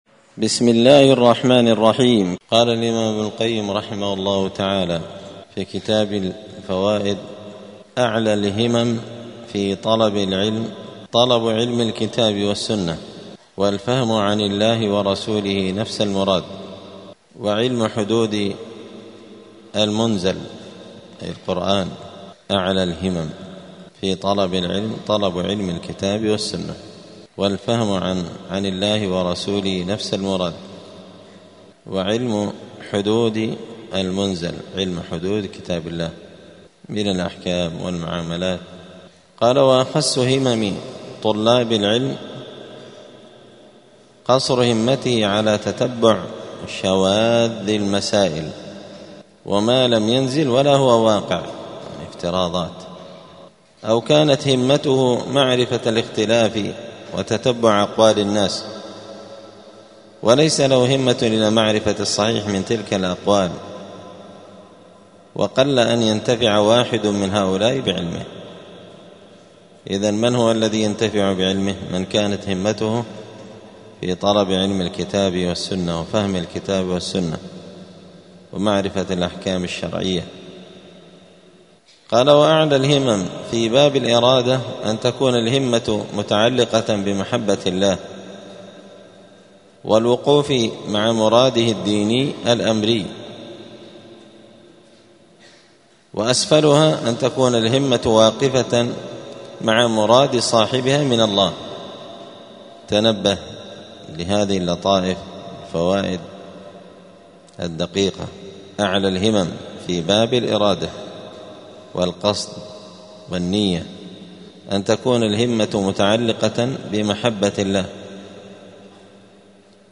الجمعة 12 جمادى الآخرة 1446 هــــ | الدروس، دروس الآداب، كتاب الفوائد للإمام ابن القيم رحمه الله | شارك بتعليقك | 29 المشاهدات
دار الحديث السلفية بمسجد الفرقان قشن المهرة اليمن